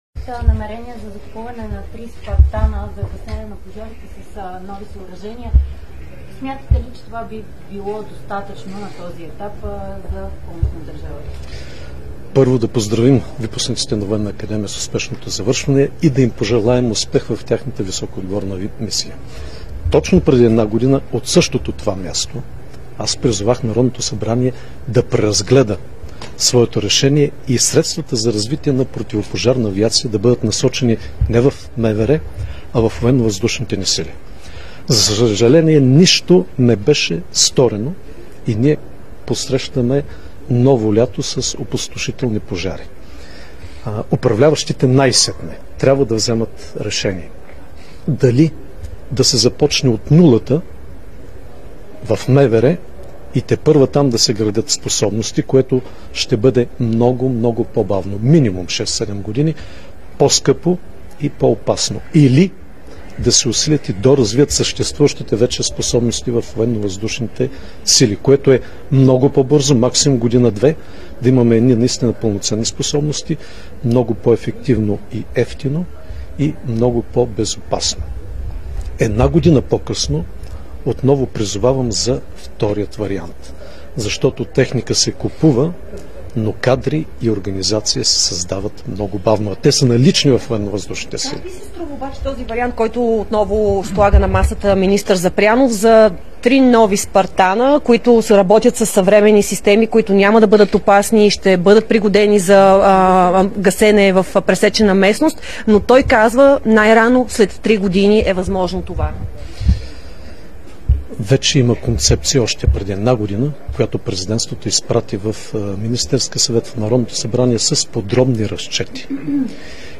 Briefing Rumen Radev 12 55H 31 07 25
BRIEFING_RUMEN_RADEV_12.55H_31.07.25.mp3